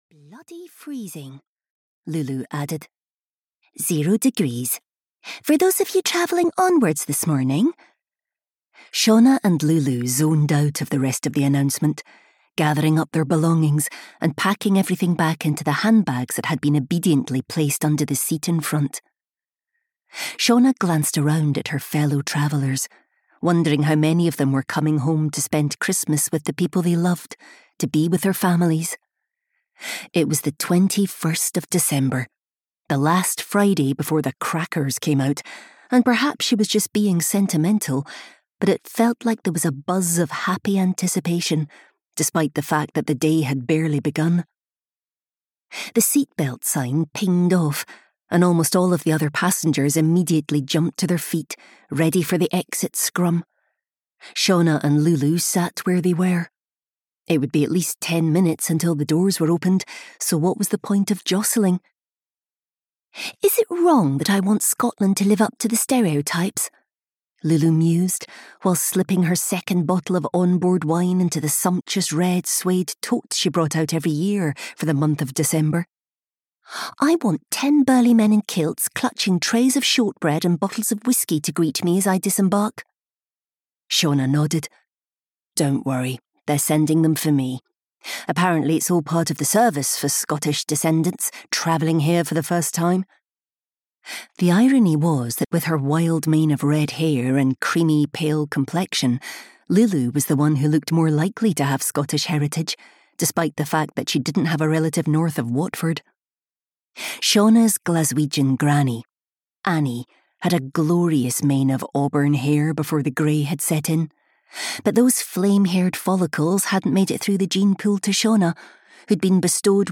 Audio knihaAnother Day in Winter (EN)
Ukázka z knihy